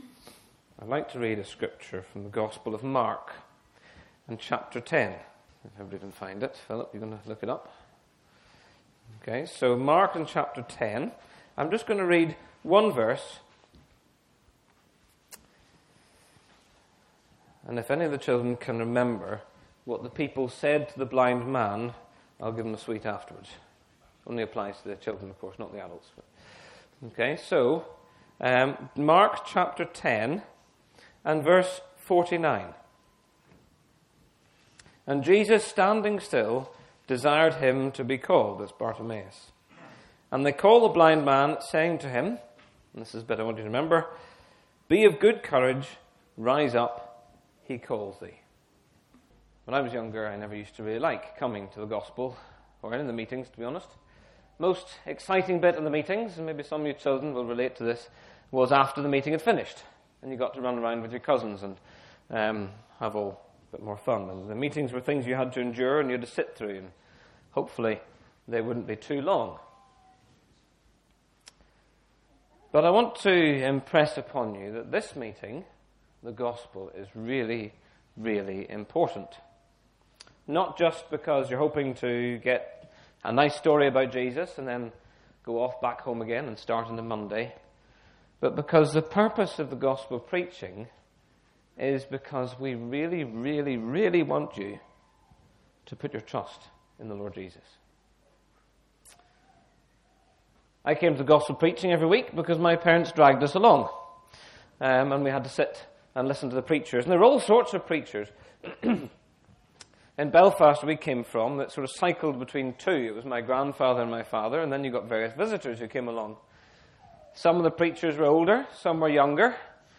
In this Gospel preaching you will hear the true story when Jesus Heals Blind Bartimaeus.